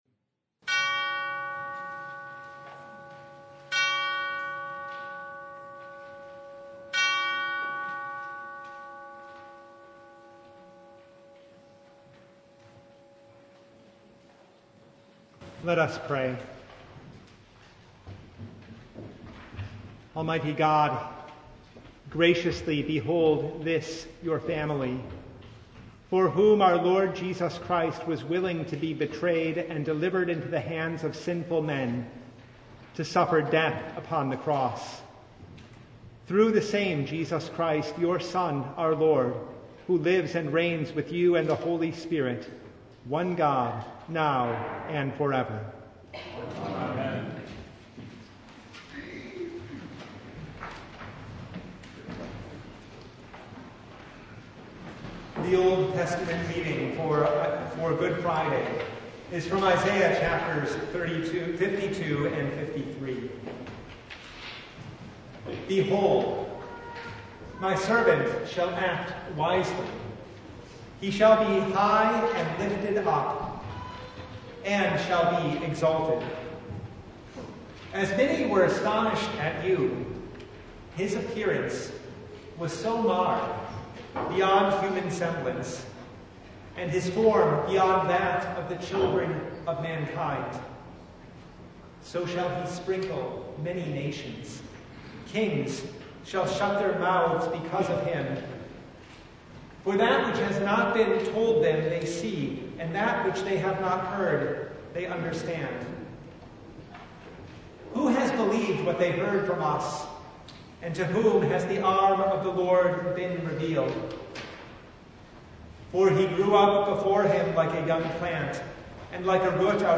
Good Friday Chief Service (2025)
Download Files Bulletin Topics: Full Service « The Lamb King »